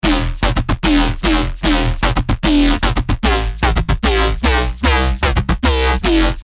❇ Ready for BASS HOUSE, TRAP, UKG, DRUM & BASS, DUBSTEP and MORE!
RH - Chompon [Emin] 150BPM
RH-Chompon-Emin-150BPM.mp3